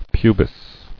[pu·bis]